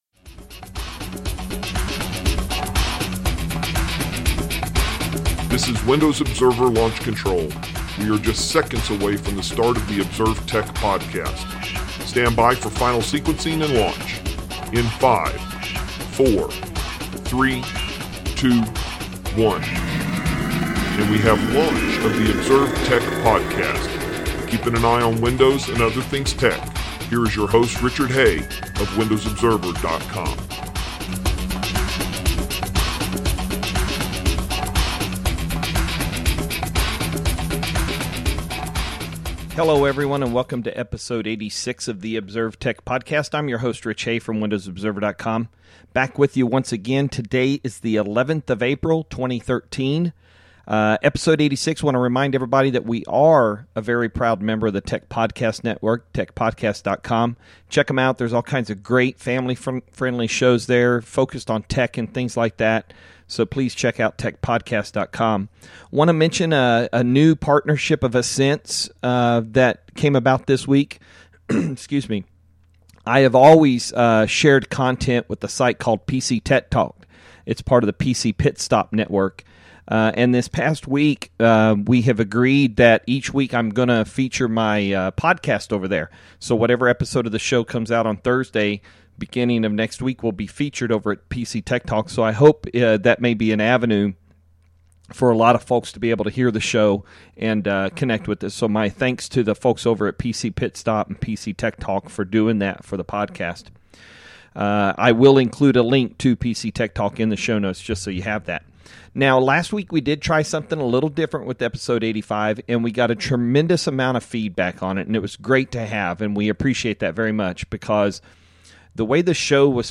Every piece of it was positive for the less rapid fire pace of the show so we will stick with that new format.